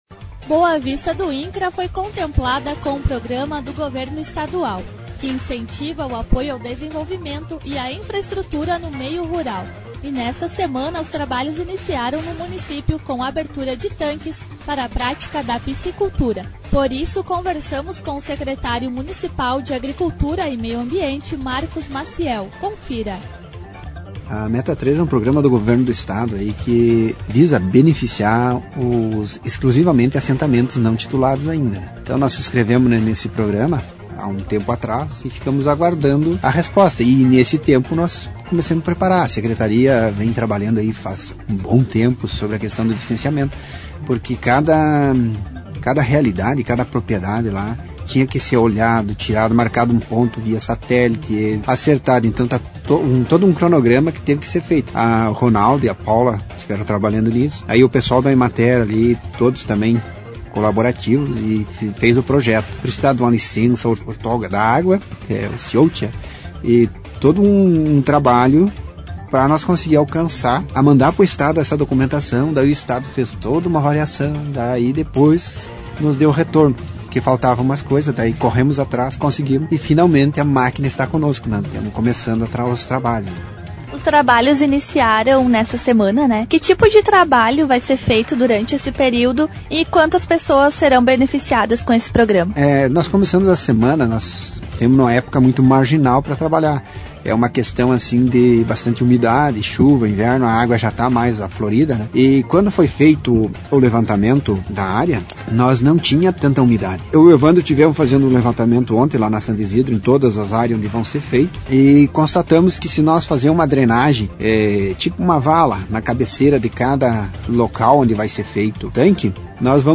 Entrevista com o Secretário Municipal de Agricult... mp3 Publicado em 17/09/18 Formato: audio/mpeg